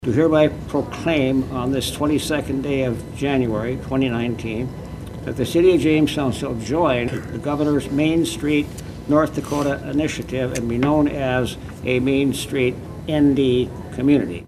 Later during the Finance & Legal Committee meeting, committee Chairman Dan Buchanan read a proclamation by Mayor Dwaine Heinrich proclaiming Jamestown to be a Main Street City.